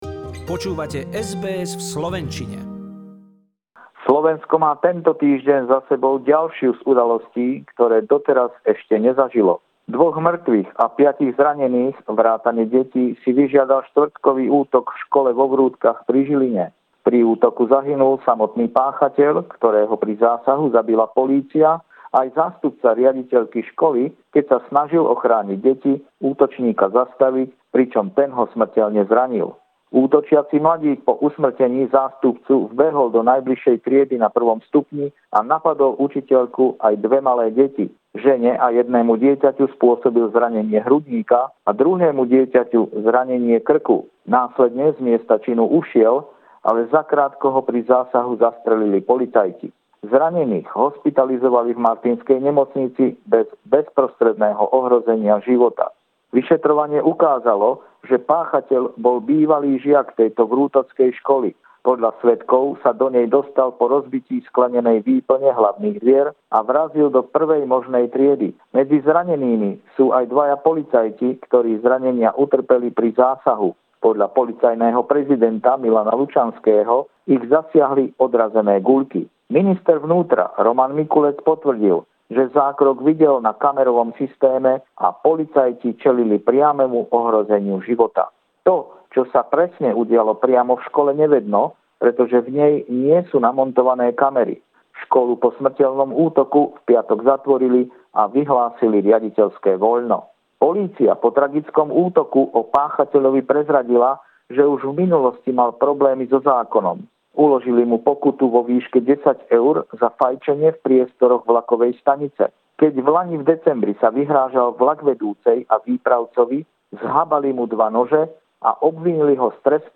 Regular stringer report